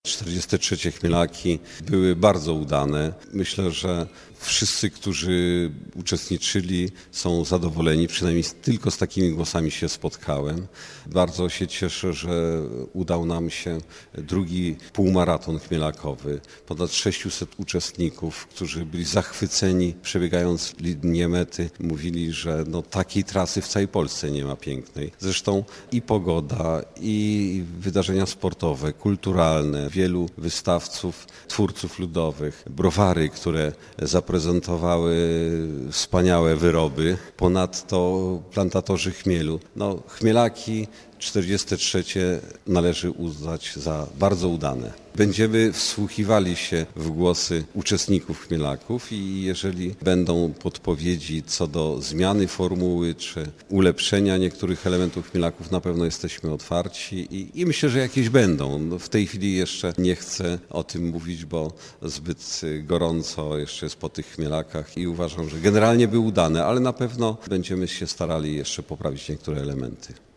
Chmielaki przechodzą do historii. Tegoroczna edycja była udana pod każdym względem, a ewentualne organizacyjne niedociągnięcia, na przyszłość będziemy się starali poprawić - zapewnia burmistrz Krasnegostawu Andrzej Jakubiec.